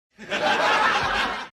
Crowd - Canned laugh